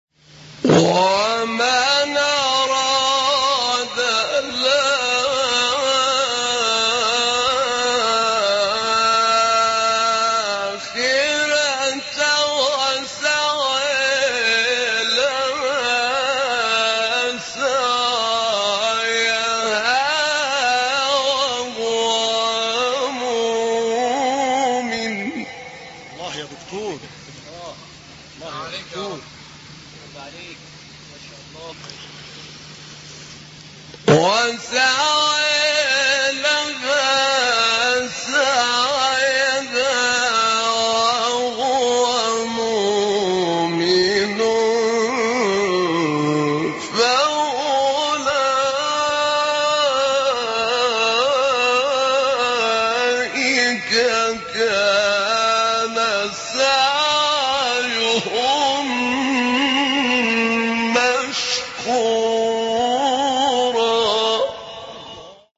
مقام : بیات